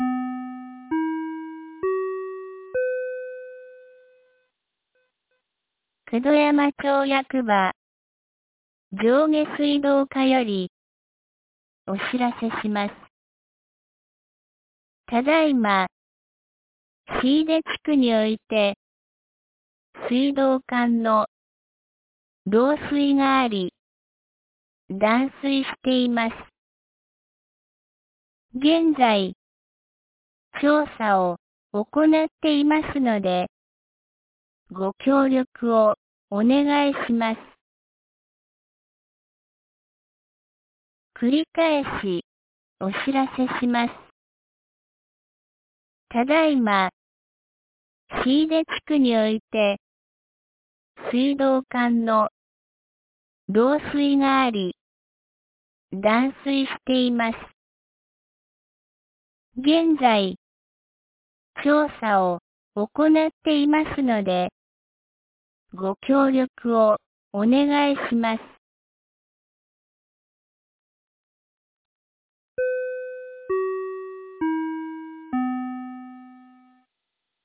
2023年06月03日 07時01分に、九度山町より下古沢地区、椎出地区へ放送がありました。